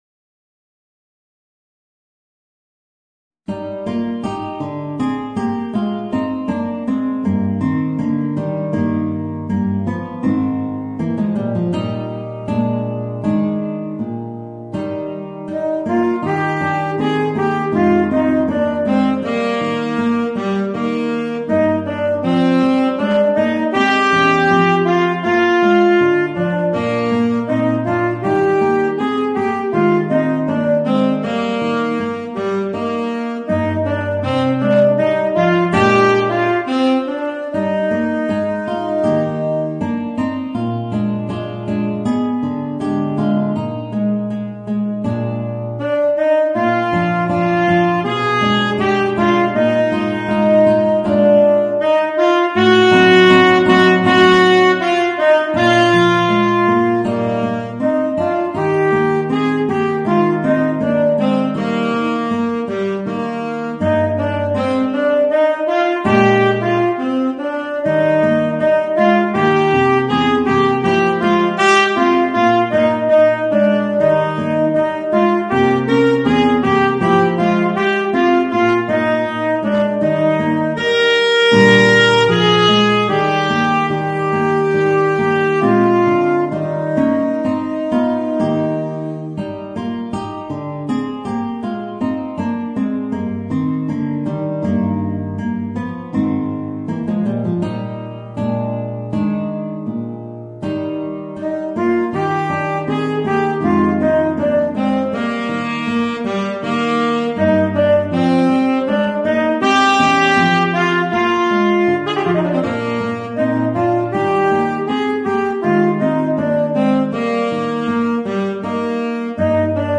Voicing: Guitar and Tenor Saxophone